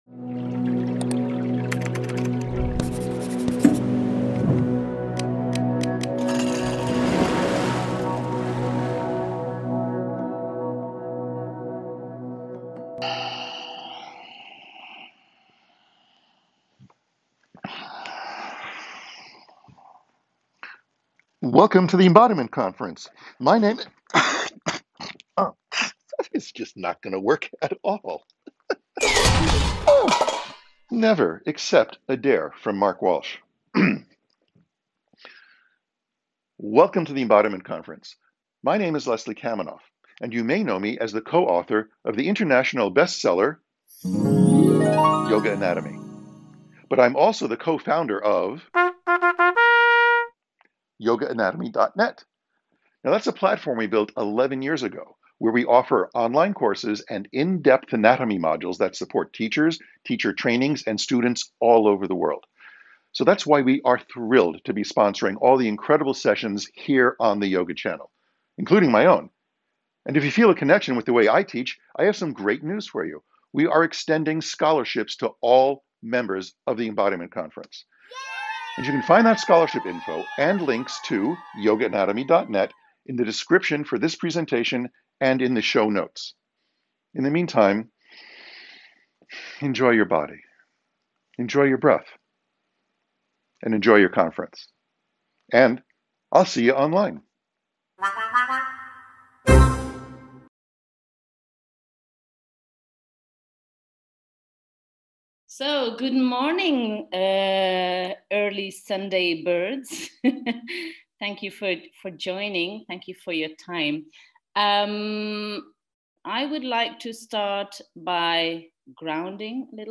How to teach yoga to kids to keep them engaged. Intermediate understanding Some standing/ movement Likely soothing Join a short sample of a typical kids yoga class.
Session will be fun, interactive and accessible for both new and more experienced teachers.